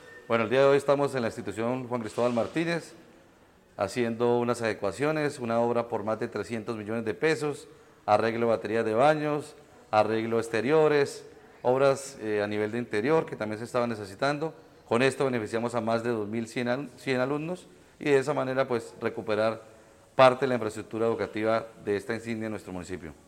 Carlos Román - Alcalde de Girón.mp3